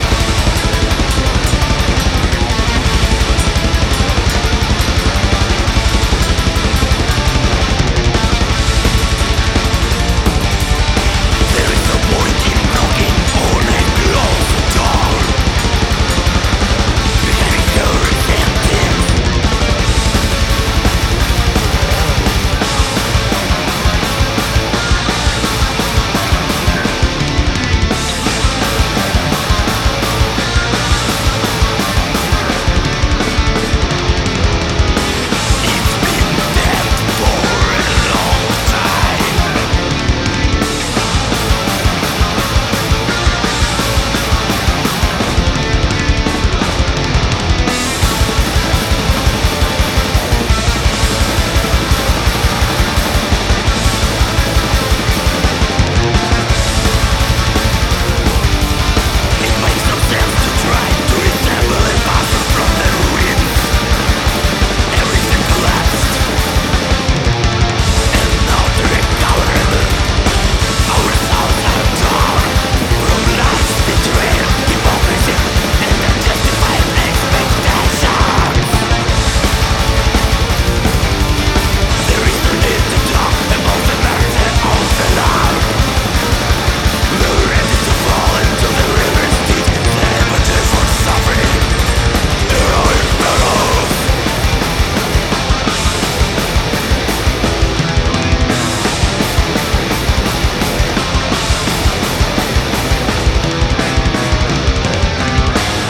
Кусочек блэка...
..и тут мы плавное возвращаемся к вопросу баса и бочки :Dle46: